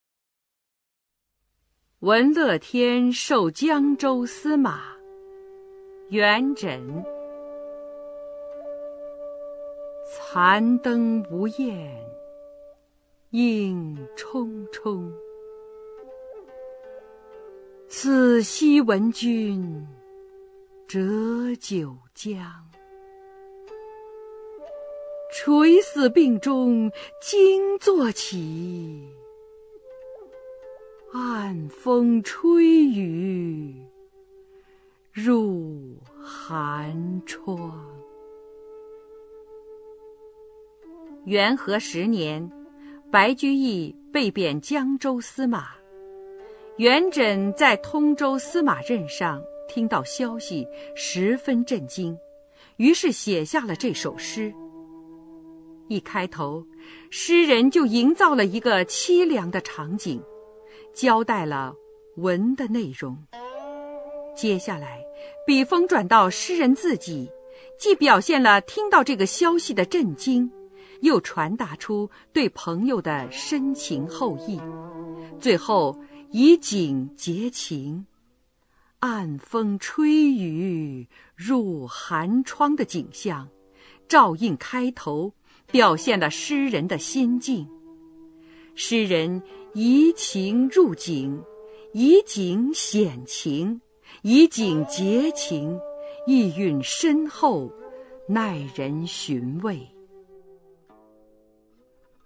[隋唐诗词诵读]元稹-闻乐天授江州司马 配乐诗朗诵